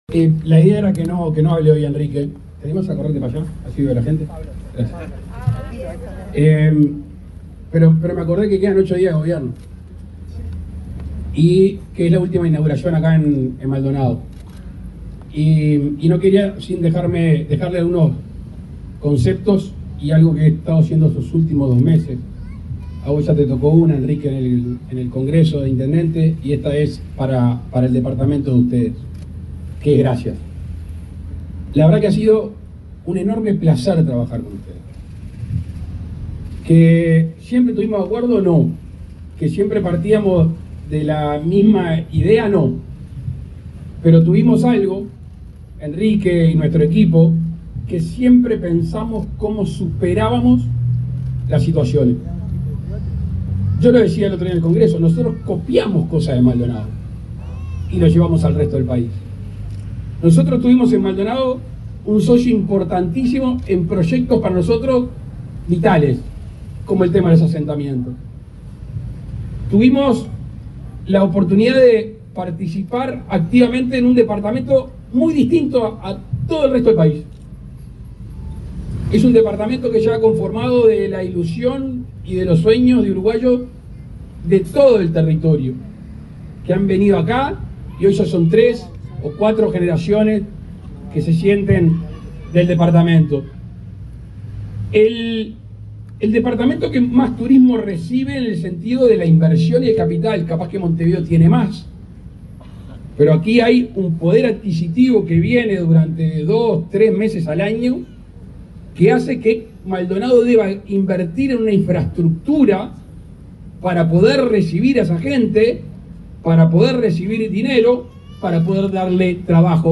Palabras del presidente Luis Lacalle Pou
El presidente de la República, Luis Lacalle Pou, encabezó el acto de inauguración de una piscina en el Campus de Maldonado, en el departamento